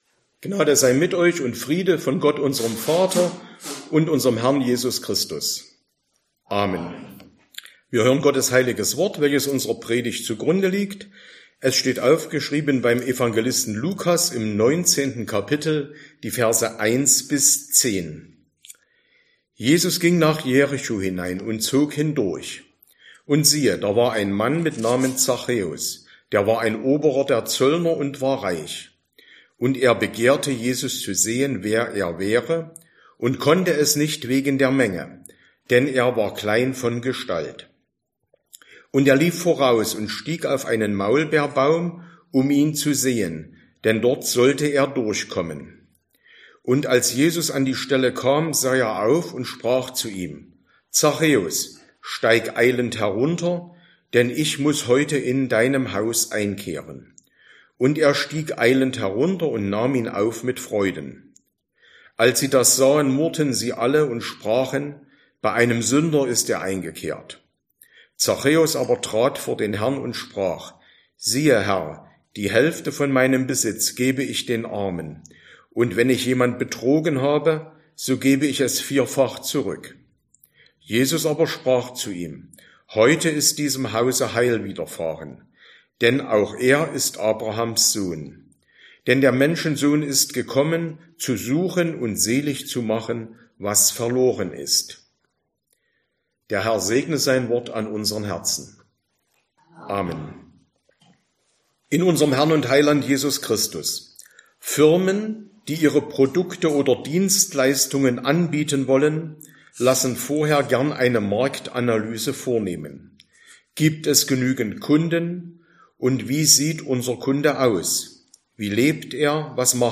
Sonntag nach Trinitatis Passage: Lukas 19, 1-10 Verkündigungsart: Predigt « 1.